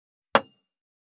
205,机に物を置く,テーブル等に物を置く,食器,グラス,コップ,工具,小物,雑貨,コトン,トン,ゴト,ポン,ガシャン,ドスン,ストン,カチ,タン,バタン,スッ,サッ,コン,ペタ,パタ,チョン,コス,カラン,ドン,チャリン,効果音,環境音,BGM,
コップ効果音物を置く